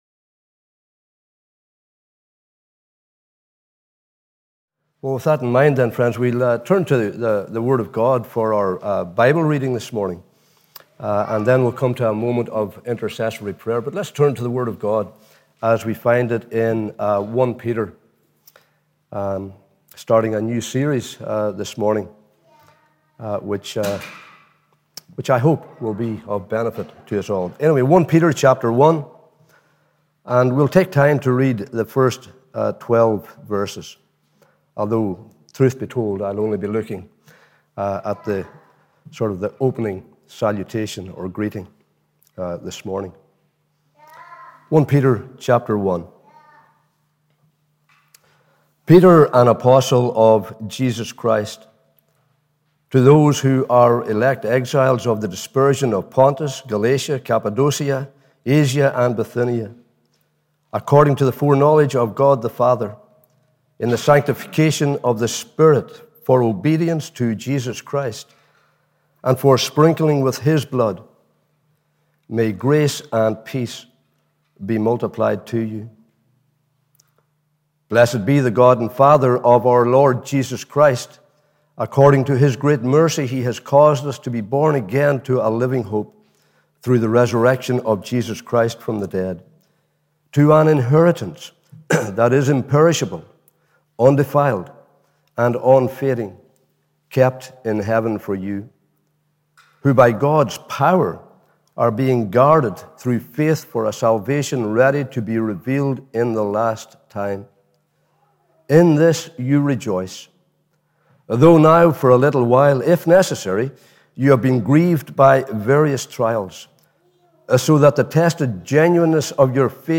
Morning Service 3rd October 2021 – Cowdenbeath Baptist Church